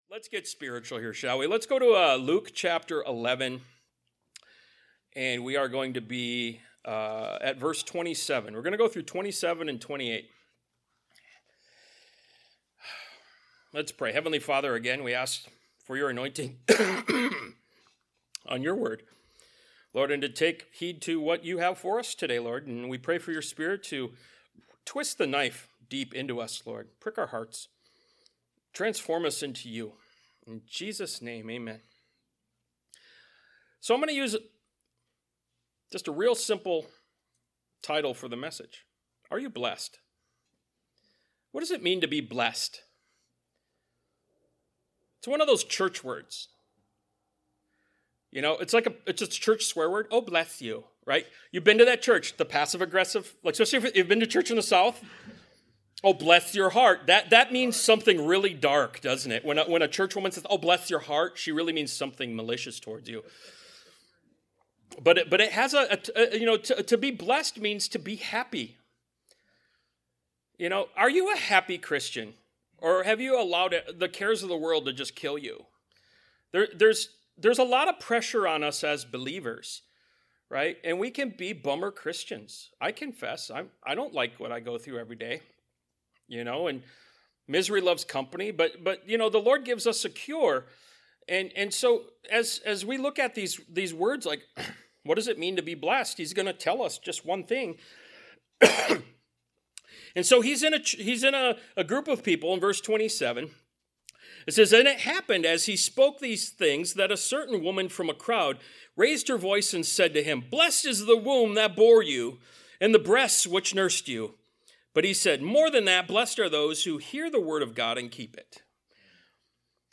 Ministry of Jesus Service Type: Sunday Morning « “Demons” Ministry of Jesus Part 64 “Walk in the Light” Ministry of Jesus Part 66 »